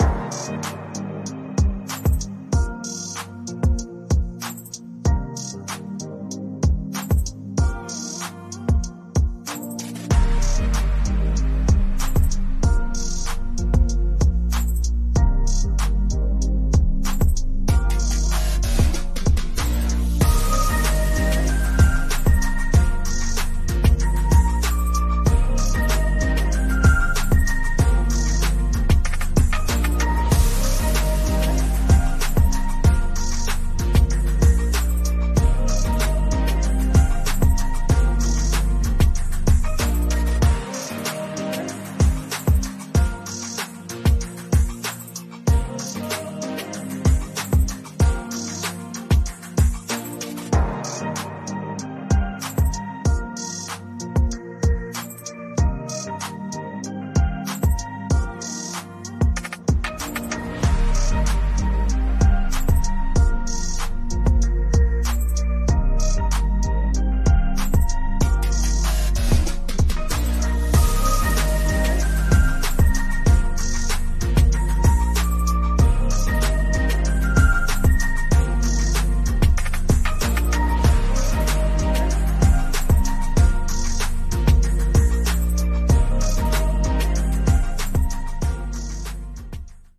タイトル・写真等のイメージにとらわれず、色々な場面の音楽・BGMで利用していただければ幸いです。